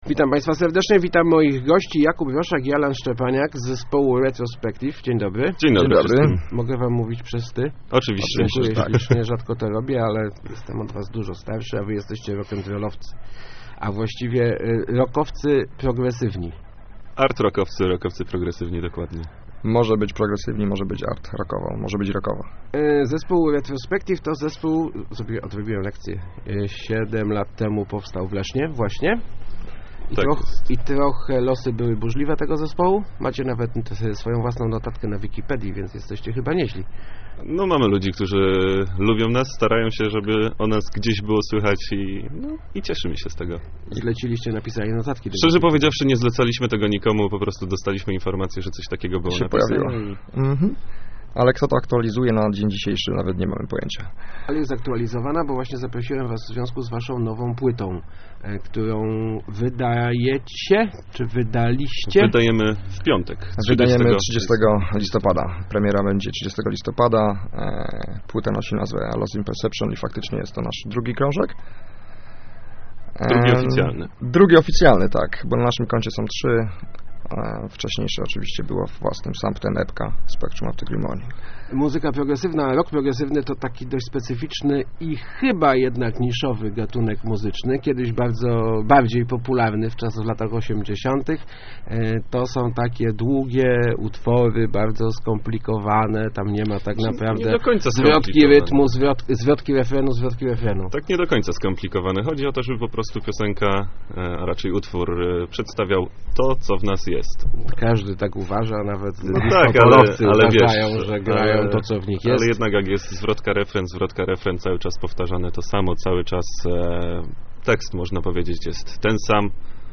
Jesteśmy bardziej popularni za granicą niż w Polsce - mówili w Rozmowach Elki